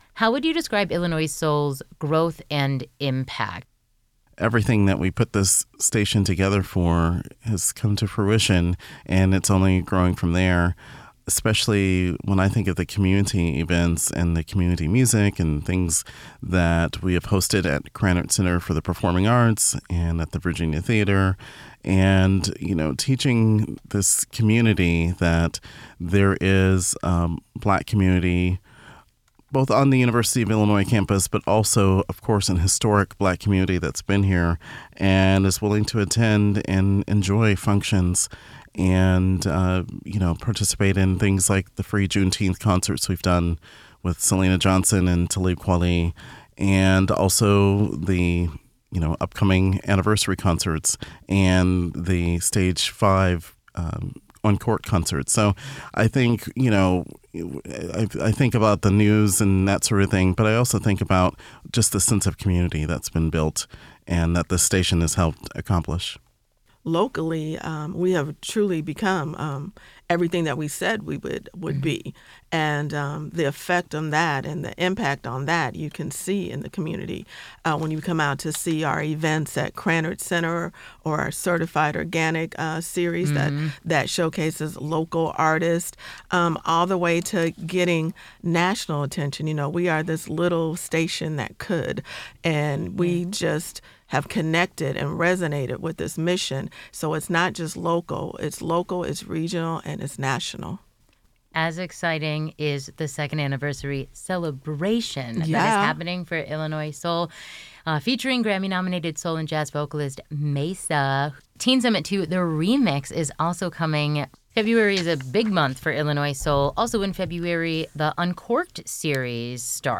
This interview has been edited for clarity and conciseness.